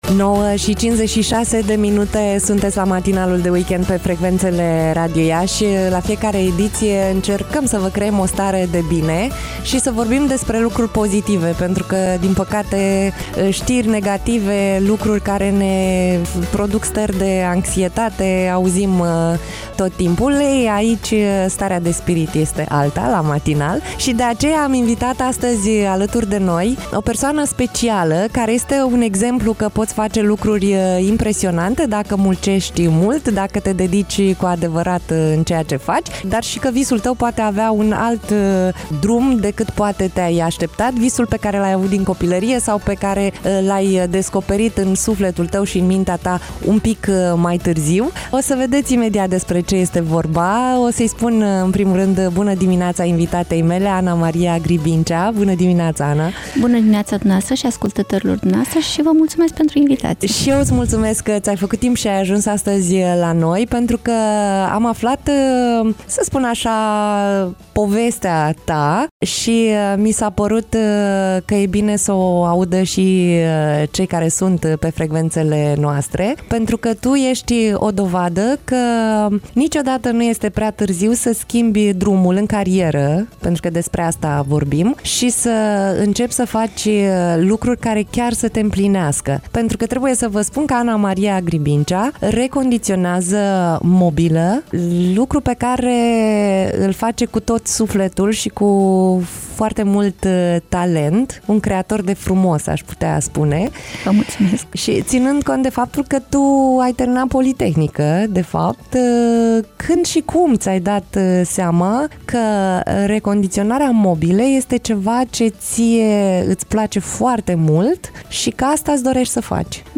(INTERVIU)